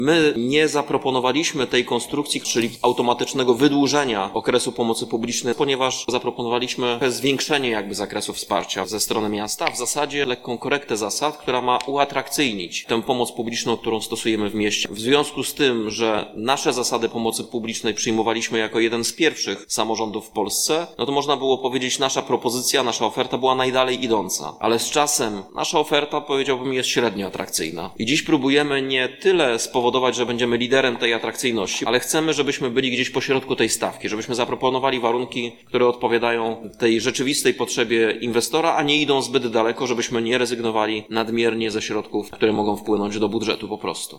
Mówi Prezydent Stargardu Rafał Zając.